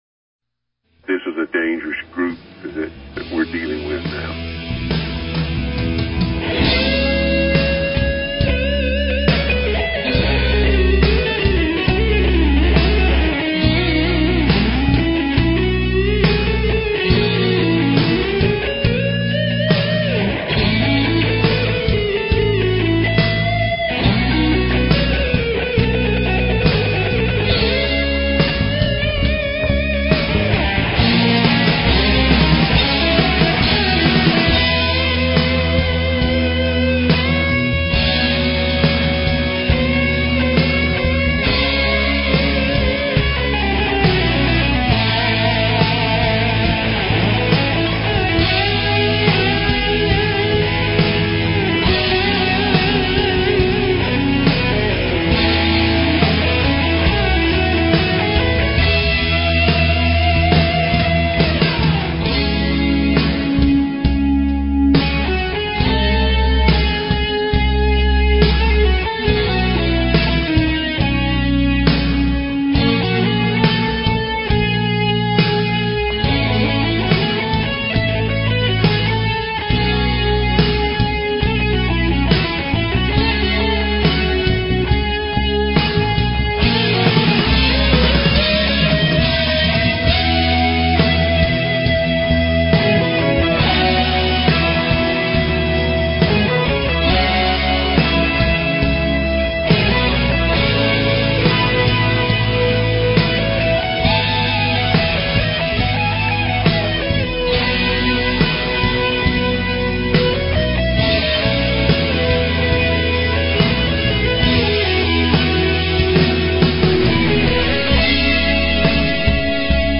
These were all done with other players I met thru Guitar War and we exchanged tracks over the web and put together some killer tunes!